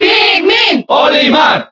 Category:Crowd cheers (SSBB) You cannot overwrite this file.
Olimar_Cheer_Spanish_SSBB.ogg